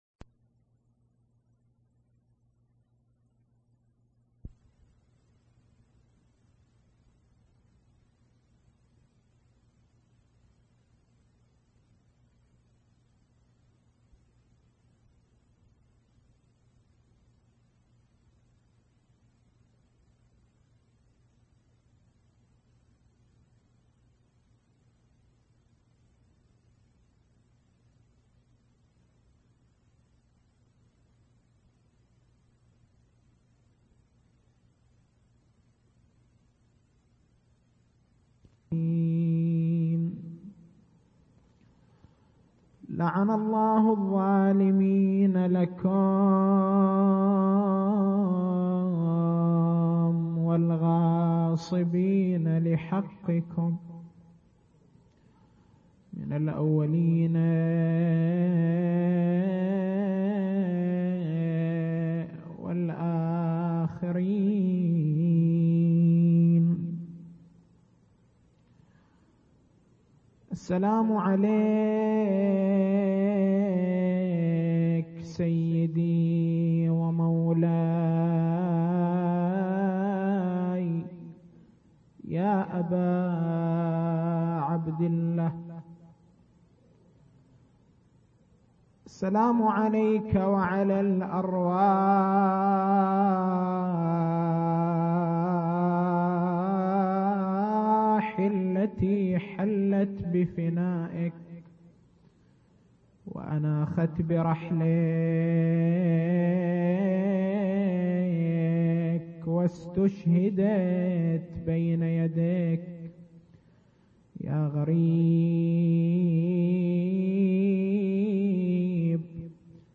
شبكة الضياء > مكتبة المحاضرات > مناسبات متفرقة > أحزان آل محمّد